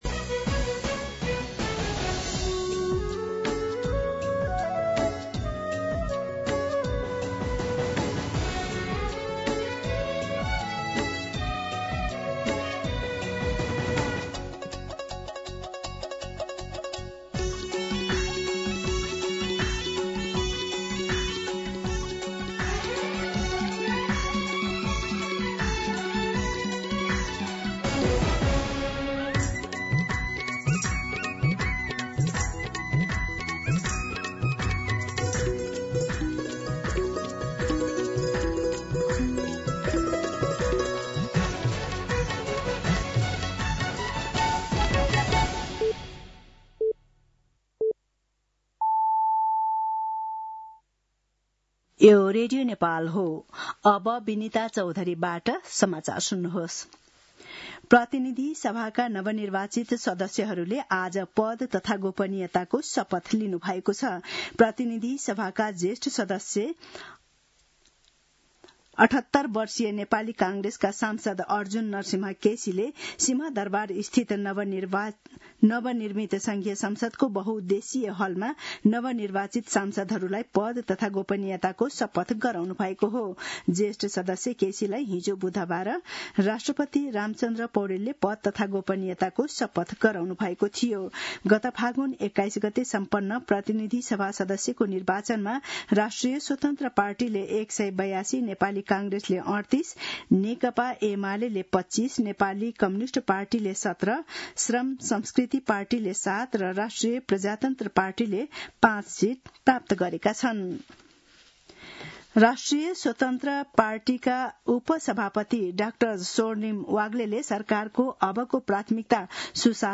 दिउँसो ४ बजेको नेपाली समाचार : १२ चैत , २०८२